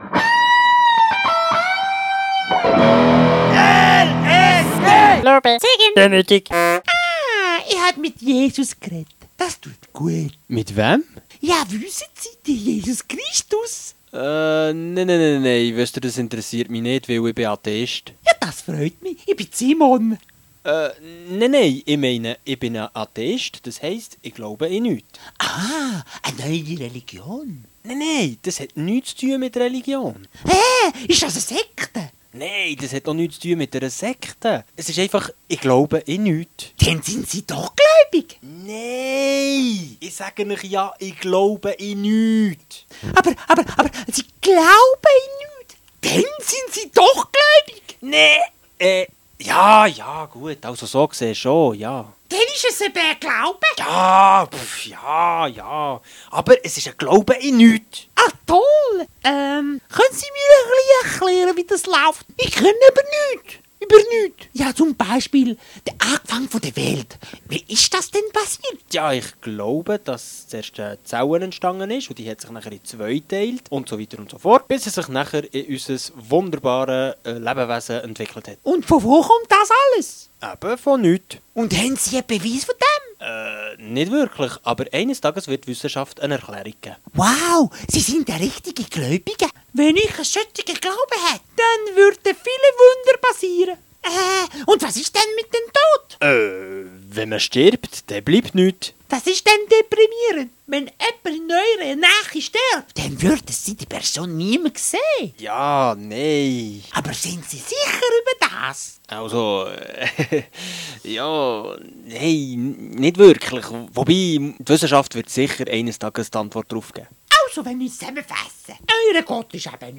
Der Sketch des Monats :